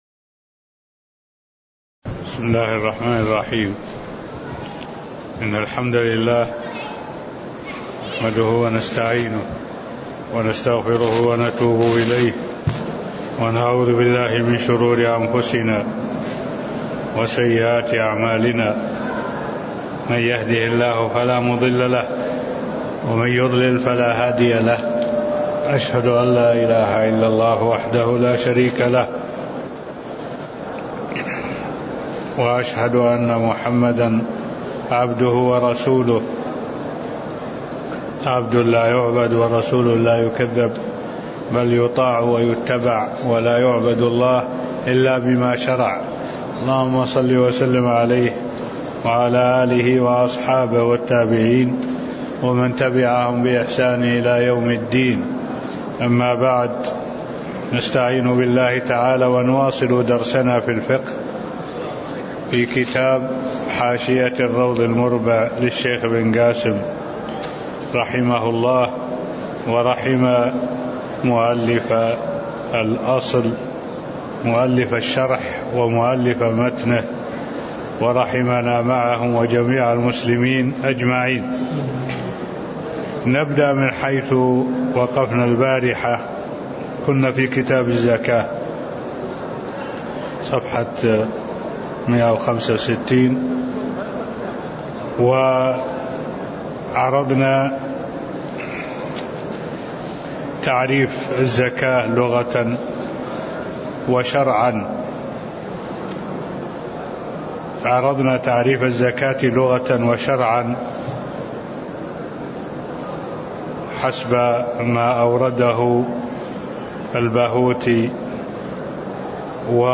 تاريخ النشر ١٥ ربيع الأول ١٤٢٩ هـ المكان: المسجد النبوي الشيخ: معالي الشيخ الدكتور صالح بن عبد الله العبود معالي الشيخ الدكتور صالح بن عبد الله العبود شروط وجوب الزكاة (008)